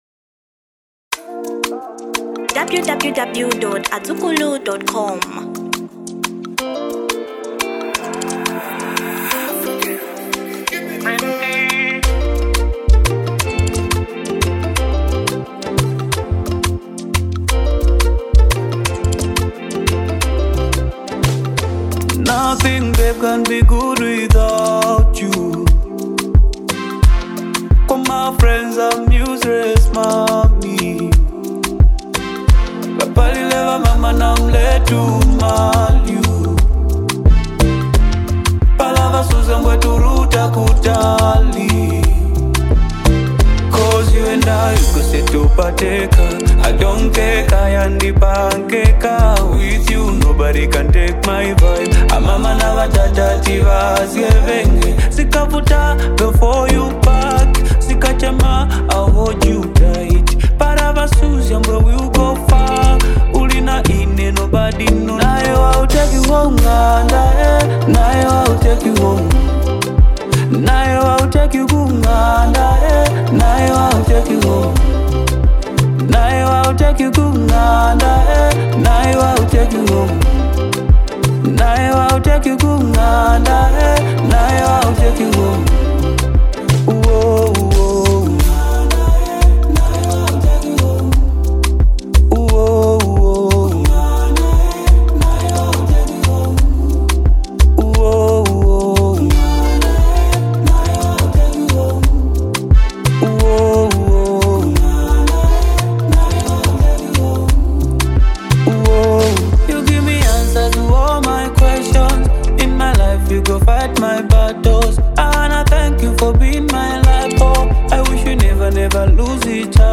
Genre Afro Pop